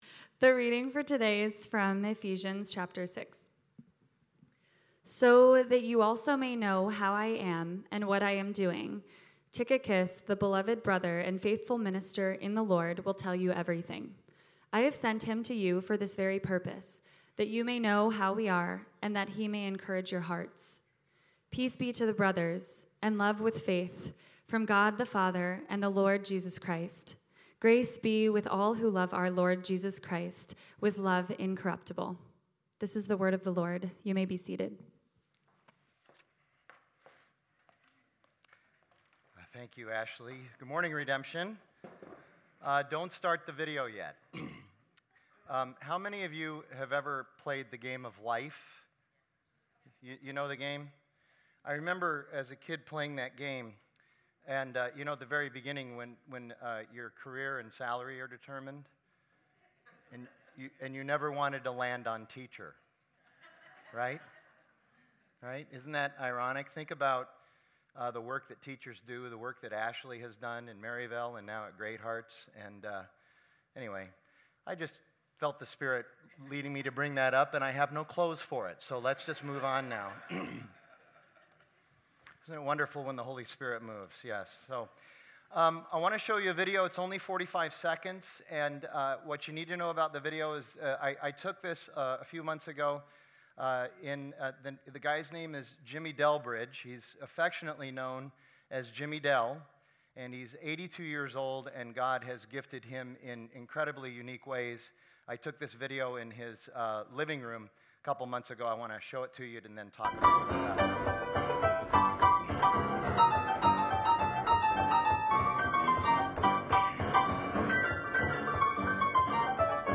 Redemption Arcadia Sermons